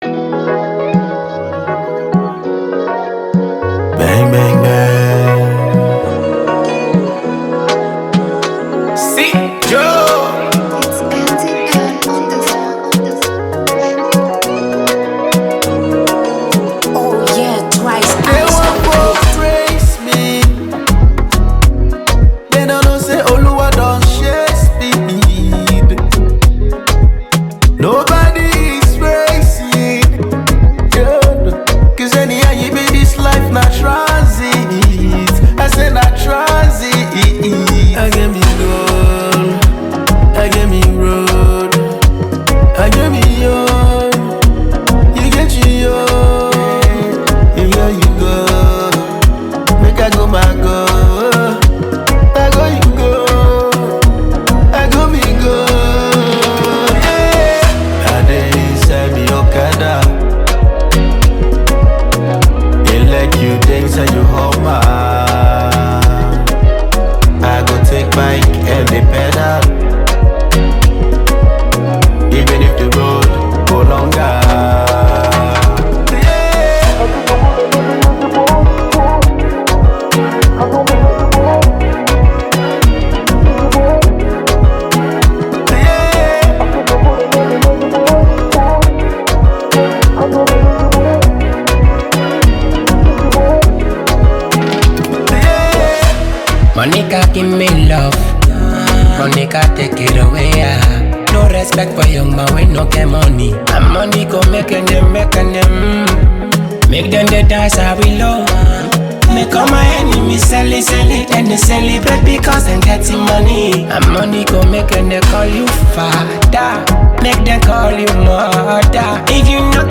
The song of the clubs